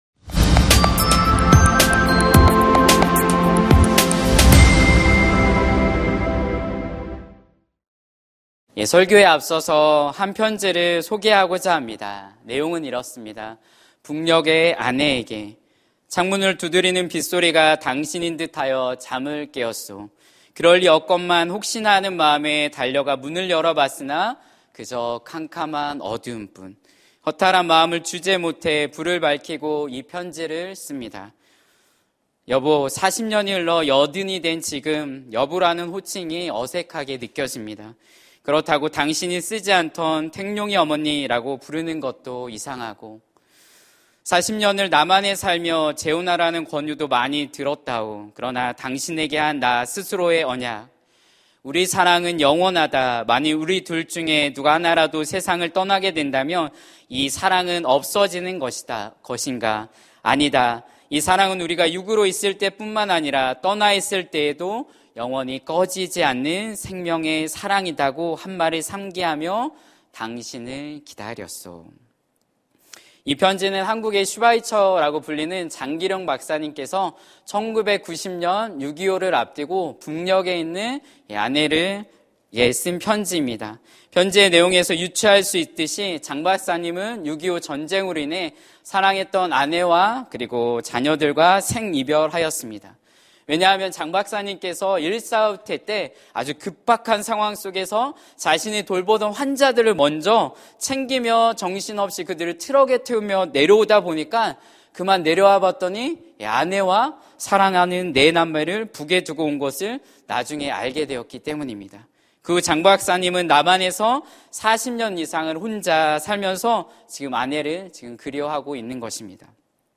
설교 : 금요심야기도회 (수지채플) 기다림 속에서... 설교본문 : 창세기 7:1-12